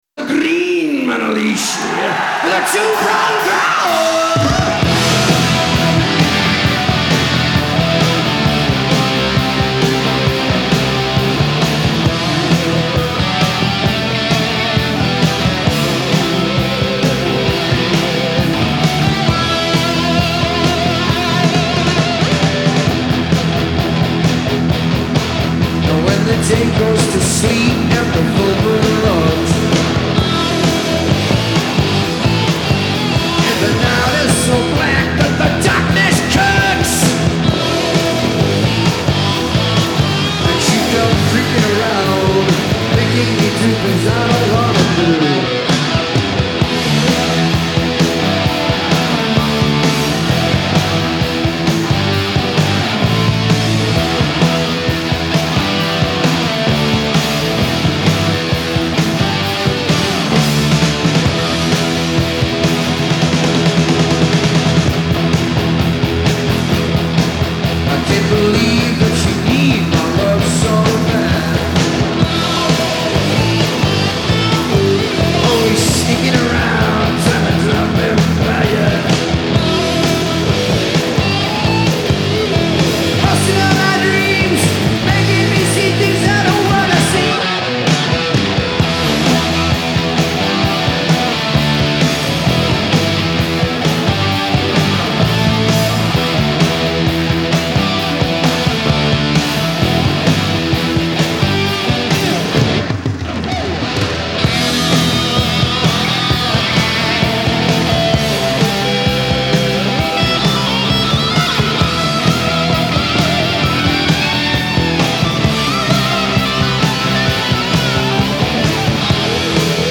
Genre : Metal
Live from Hammersmith, London, 1981